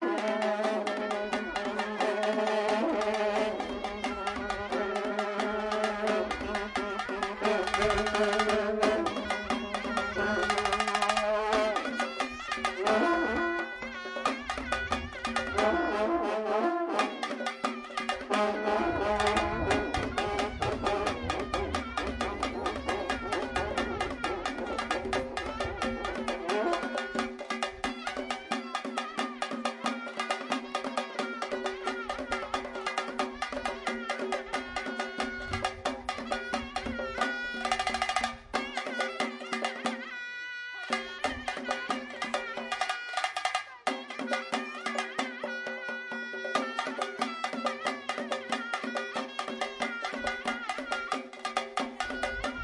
Звучание узбекской национальной музыки на карнае